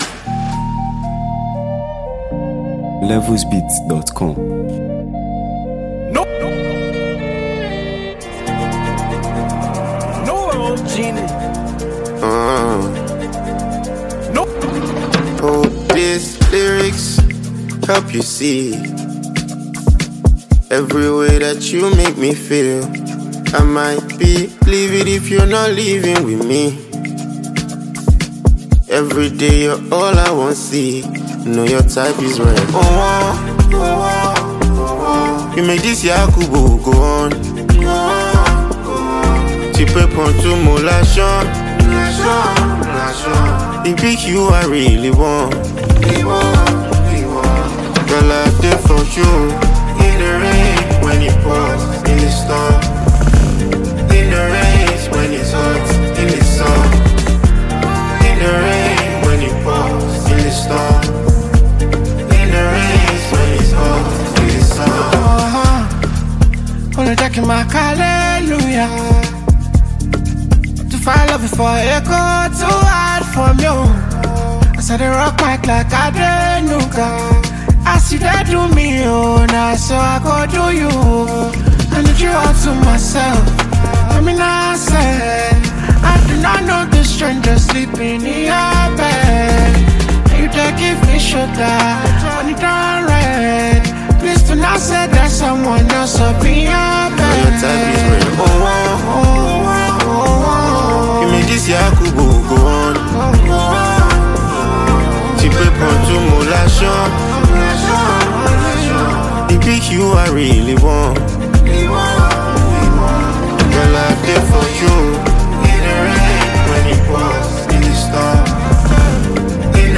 Nigeria Music
With its smooth melodies, infectious rhythm